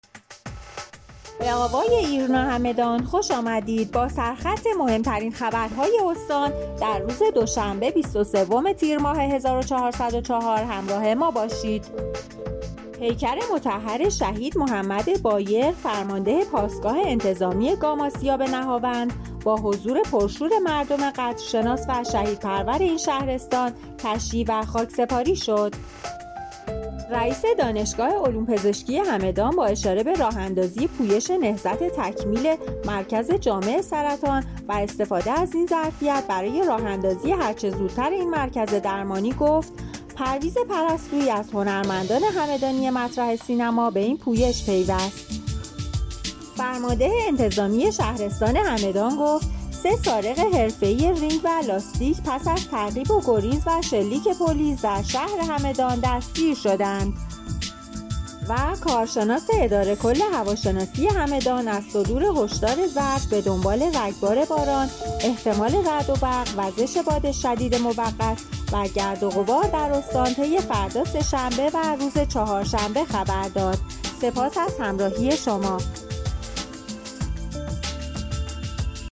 همدان-ایرنا- مهم‌ترین عناوین خبری دیار هگمتانه را هر روز از بسته خبر صوتی آوای ایرنا همدان دنبال کنید.